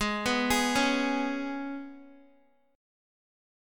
G#mbb5 Chord